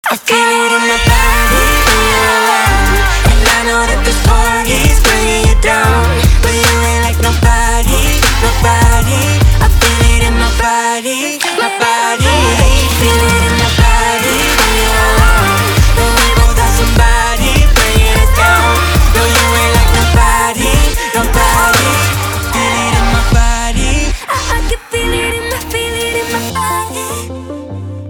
• Качество: 320, Stereo
dance
Electronic
vocal